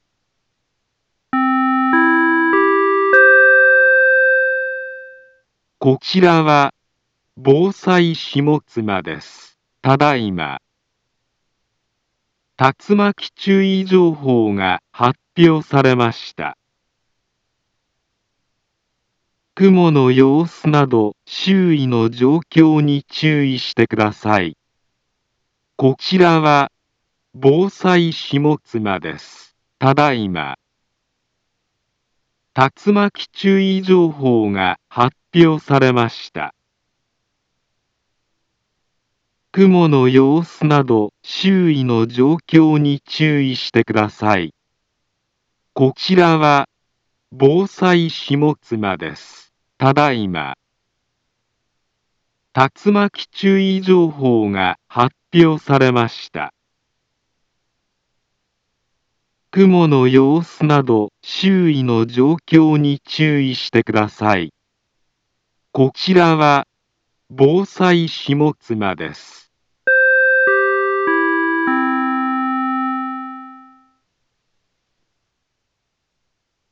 Back Home Ｊアラート情報 音声放送 再生 災害情報 カテゴリ：J-ALERT 登録日時：2024-11-27 02:04:33 インフォメーション：茨城県北部、南部は、竜巻などの激しい突風が発生しやすい気象状況になっています。